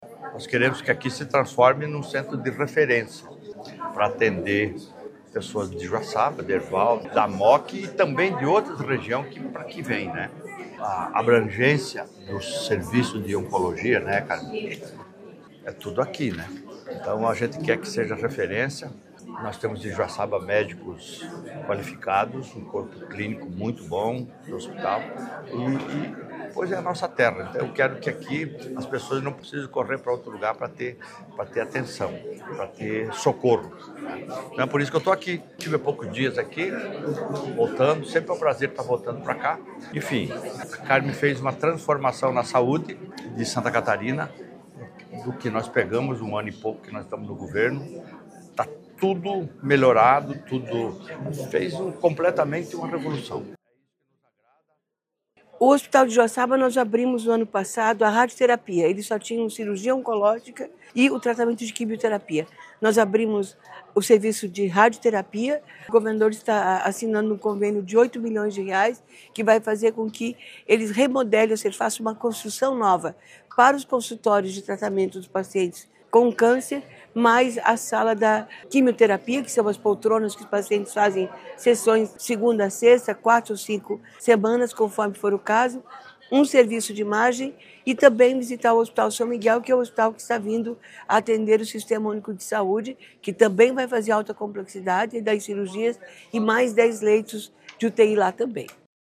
Acompanhado da secretária Carmen Zanotto e do secretário adjunto, Diogo Demarchi, o governador esteve no município no sábado, 1° de junho, e reforçou que o investimento é parte de um esforço contínuo para a melhoria dos serviços de saúde em Santa Catarina:
A secretária Carmen Zanotto, comentou sobre os serviços realizados no último ano para a população: